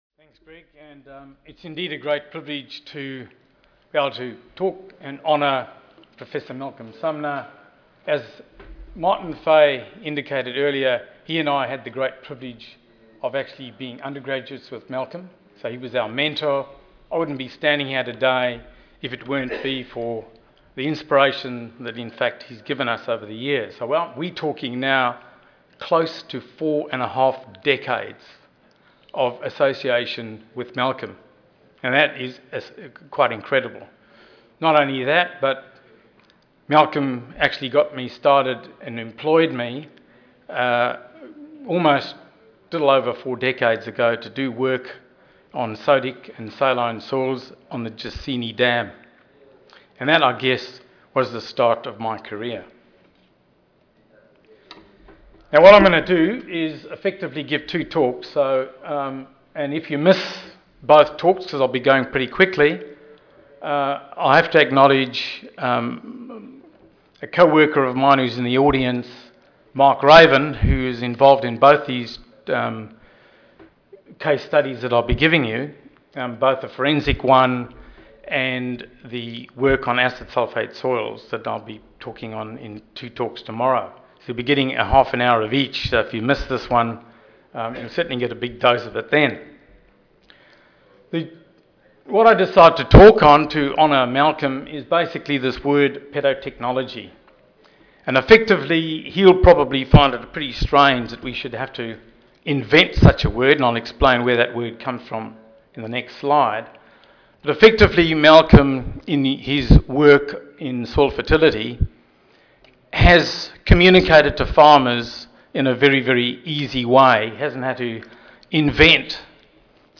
Australia Audio File Recorded presentation One goal of soil science is to apply pedological information to understand